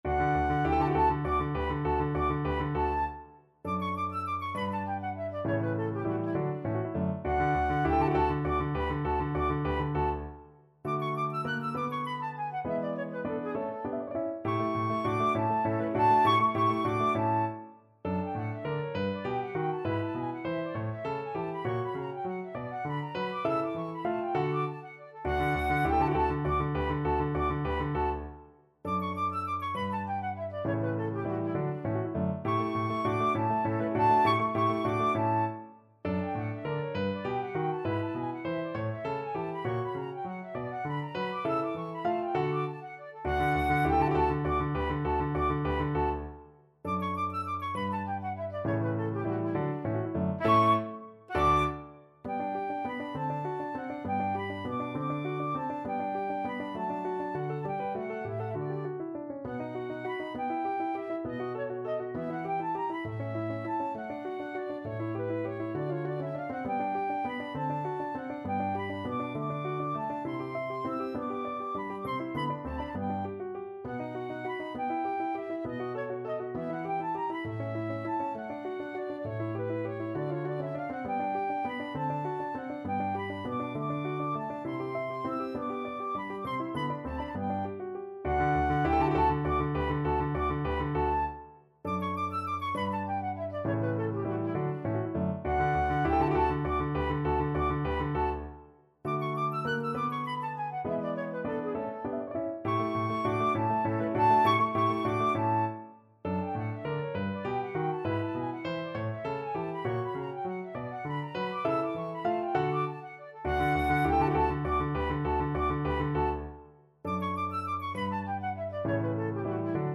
FlutePiano
Allegro vivace =200 (View more music marked Allegro)
3/4 (View more 3/4 Music)
Classical (View more Classical Flute Music)